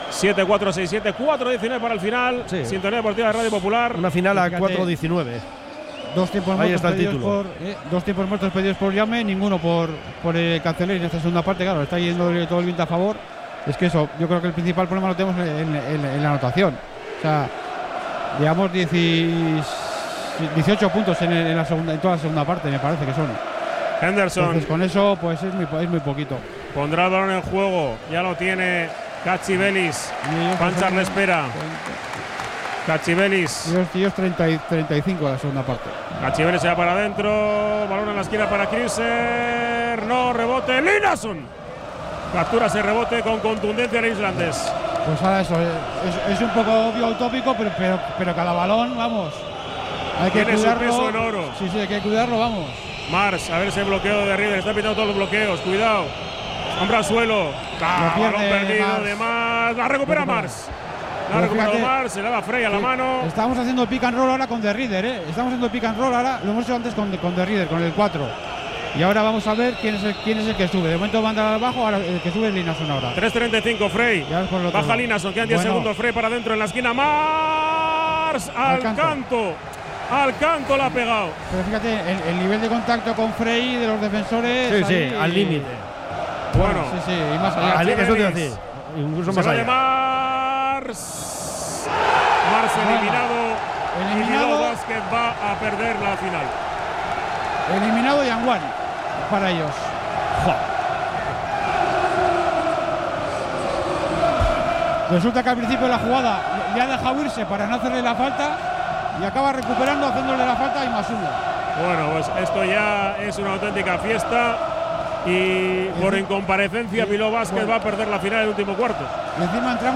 Narración final
desde Salónica con todas las reacciones de los protagonistas a pie de pista